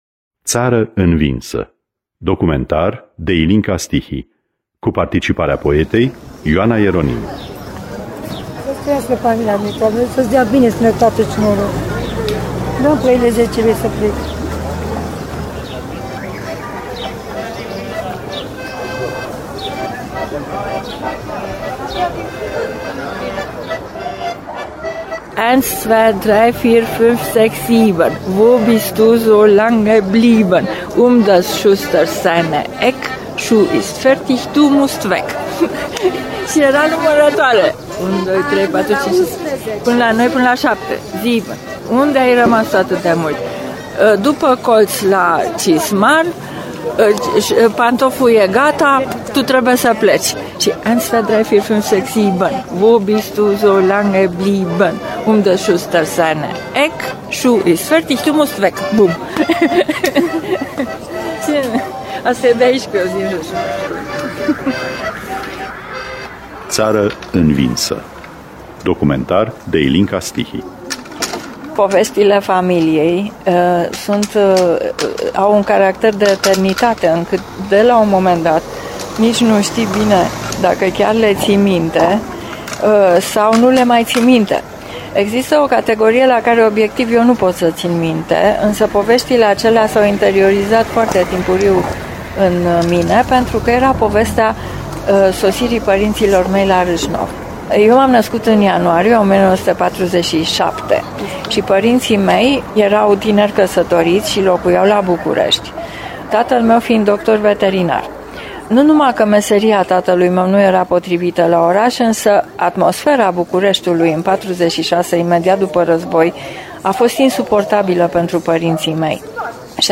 Țară învinsă. Scenariu radiofonic